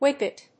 音節whip・pet 発音記号・読み方
/(h)wípɪt(米国英語), wípwípɪt(英国英語)/